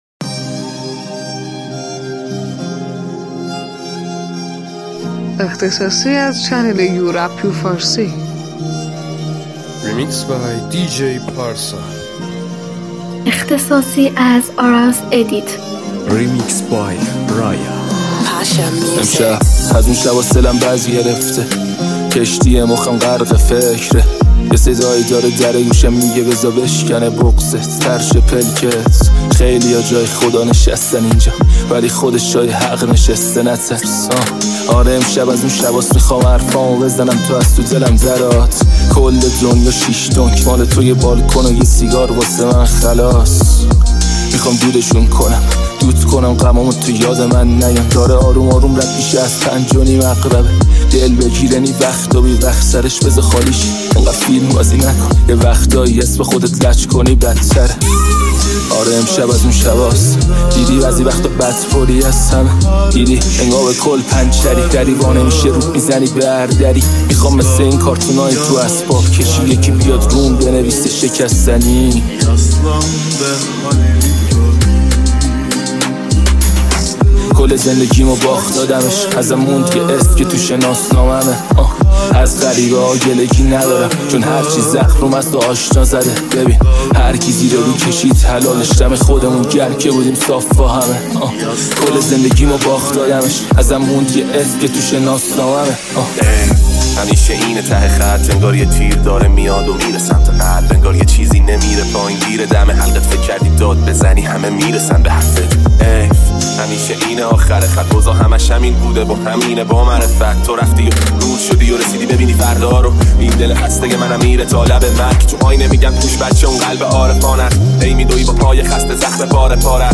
دانلود ریمیکس ترکیبی رپ